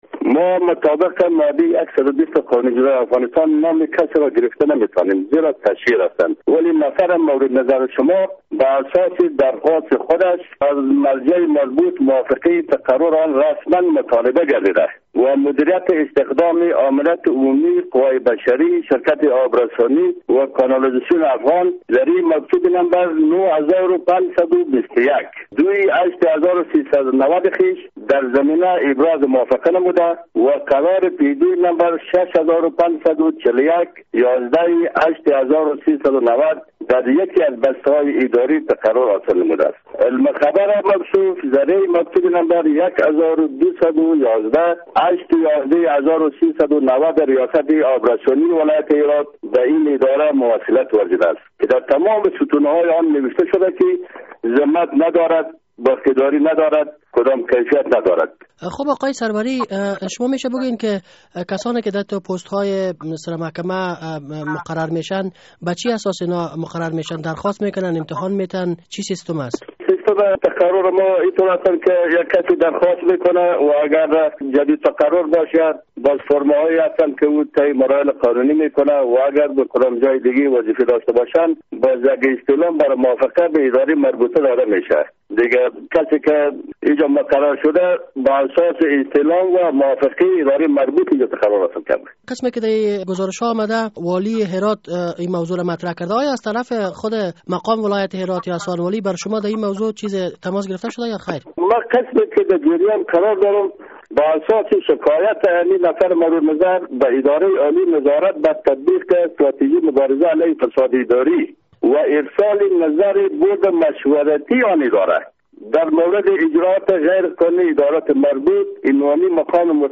مصاحبه در مورد تقرر یک فرد متهم به فساد در ستره محکمه